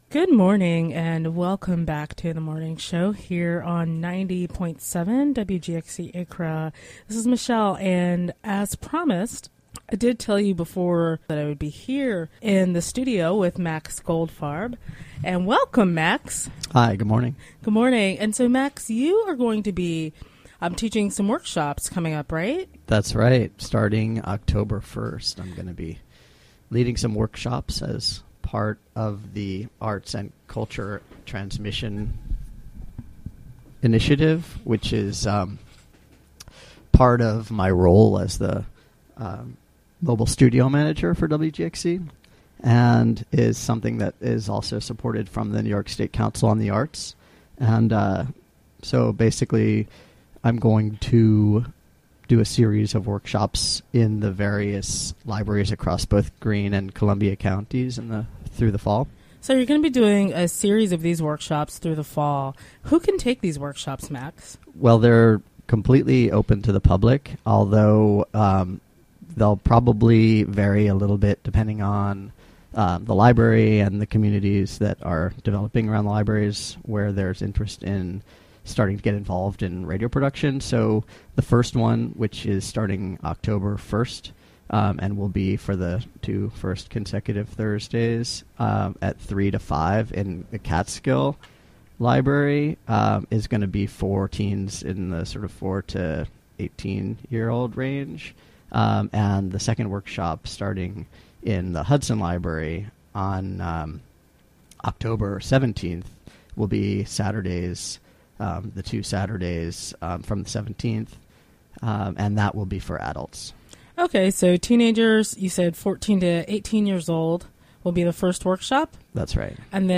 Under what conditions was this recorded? Interview recorded during the WGXC Morning Show, Fri., Sept. 25, 2015.